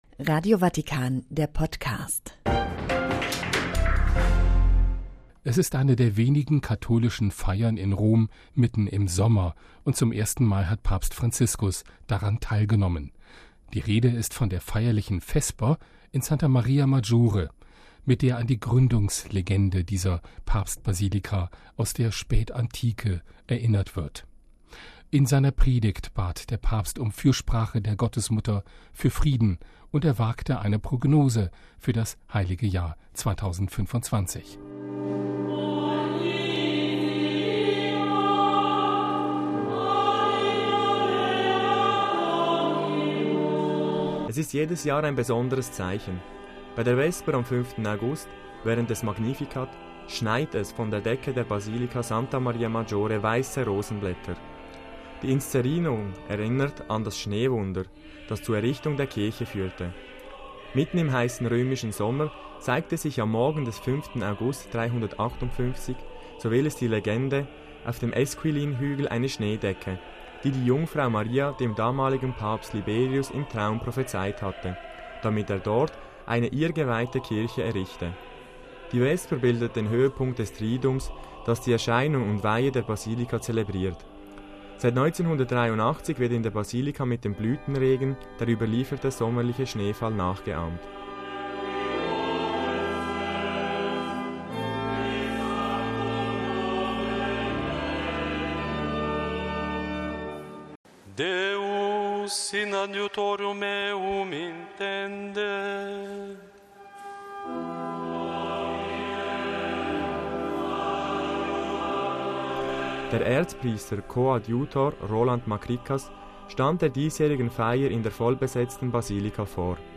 It is one of the few Catholic celebrations in Rome in the middle of summer, and for the first time Pope Francis has participated in it: at the solemn vespers in Santa Maria Maggiore, which commemorates the founding legend of the papal basilica in late antiquity. In his homily, Francis asked for the intercession of the Blessed Mother for peace and ventured a forecast for the Holy Year 2025.
The Pope and the Snow Miracle - a report by Vatican Radio